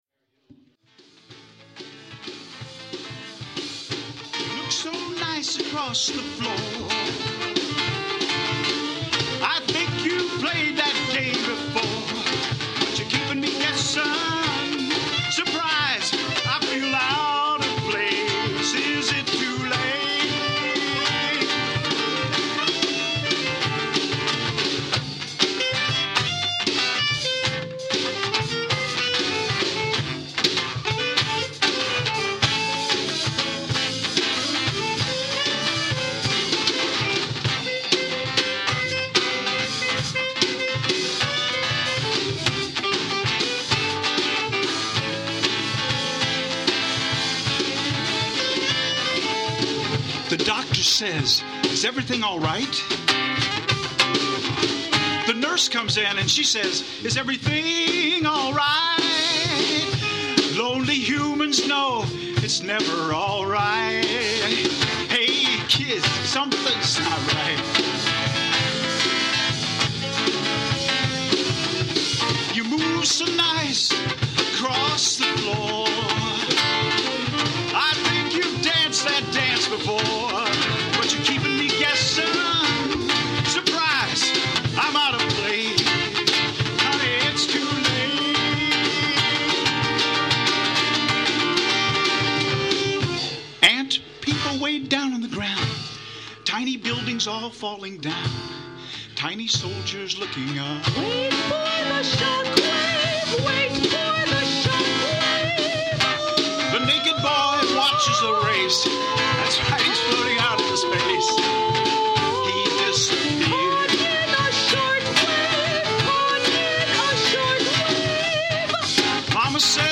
This is partly because the recording was not too good at the beginning, but also because the ensemble wasn’t exactly together until about half way through the piece. In this excerpt the balance is still bad even though I have mixed it down a bit. Too much back-up vocal and too much snare drum.
Link to mp3 of excerpt of performance of Naked Boy on Aug 5th at Lemonjellos